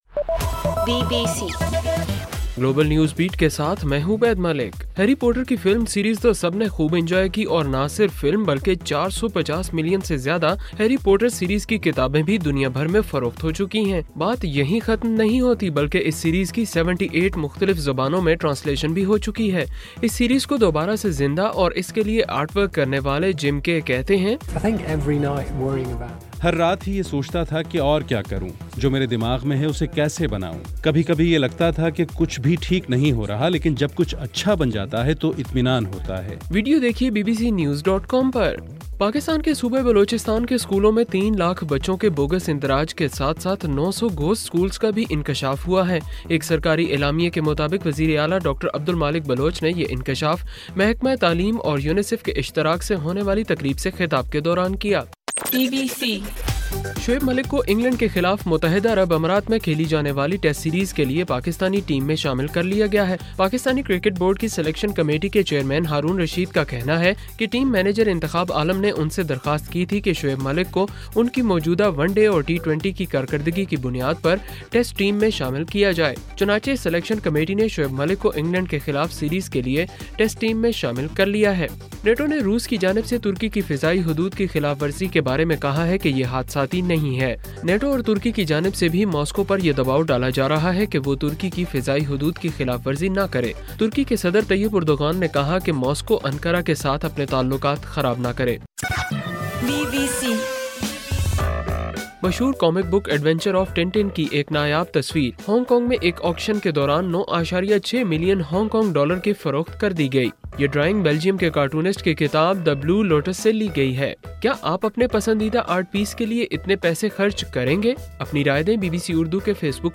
اکتوبر 6: رات 9 بجے کا گلوبل نیوز بیٹ بُلیٹن